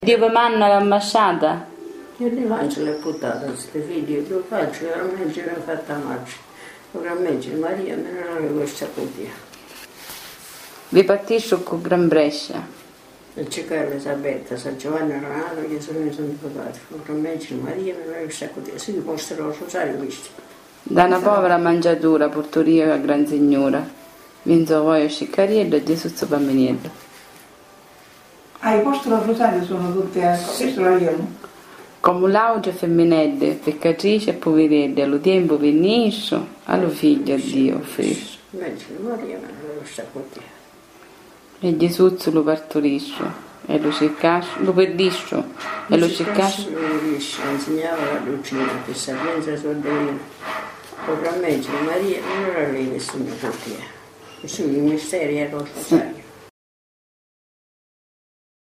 Siedo con lei in una cucina arredata con gusto.
Questa è la ragione per cui nelle registrazioni audio che la riguardano si sente spesso il mio intervento.